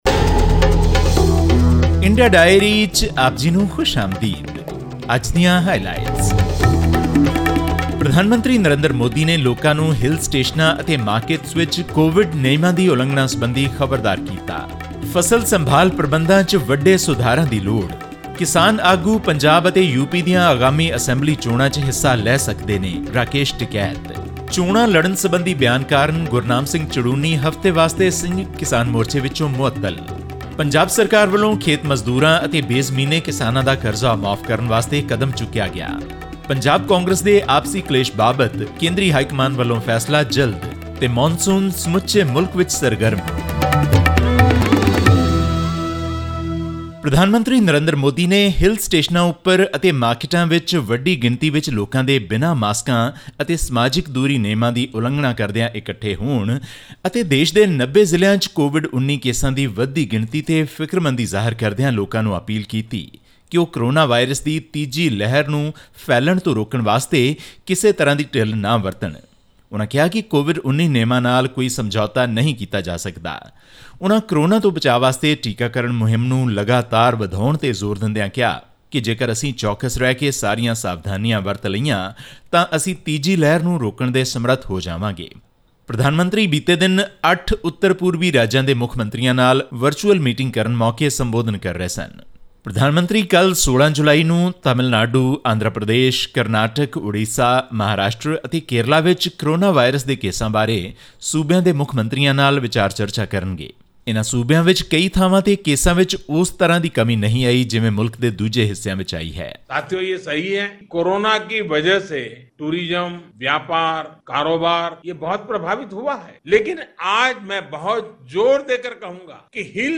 Prime Minister Narendra Modi has expressed his displeasure over people crowding at hill stations and markets without wearing masks or observing social distancing norms. All this and more in our weekly news segment from India.